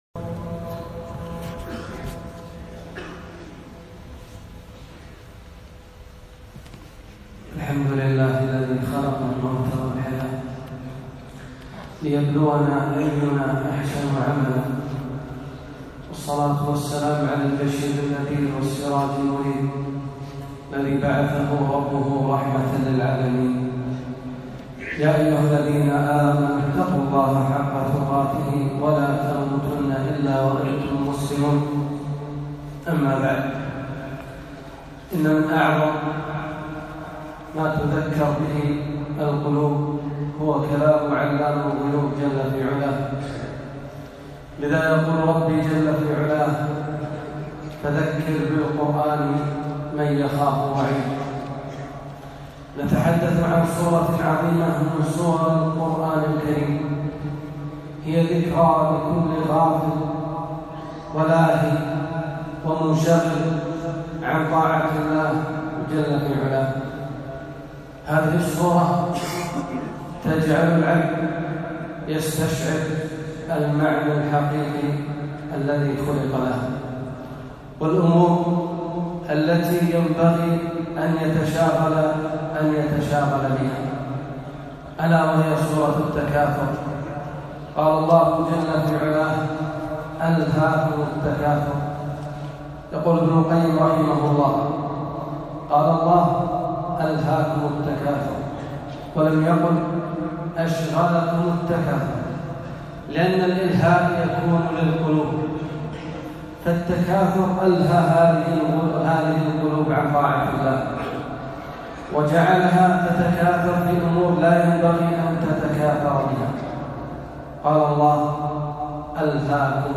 خطبة - وقفة مع سورة التكاثر